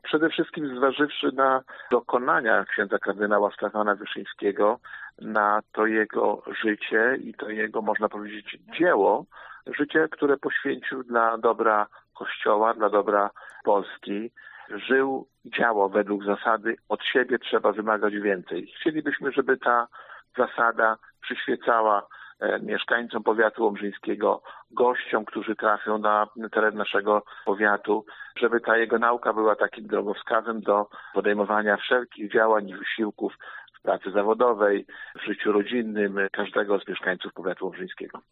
O celu tego zamiaru mówi wnioskodawca, Starosta Łomżyński, Lech Szabłowski: